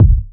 Rich Low End Kickdrum Sound D# Key 10.wav
Royality free bass drum tuned to the D# note. Loudest frequency: 97Hz
rich-low-end-kickdrum-sound-d-sharp-key-10-PKZ.mp3